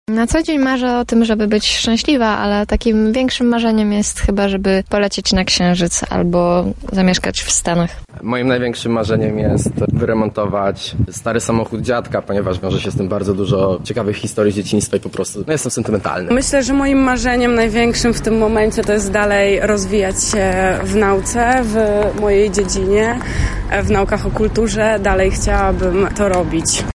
Nasza reporterka zapytała mieszkańców Lublina o to, jakie są ich marzenia:
Sonda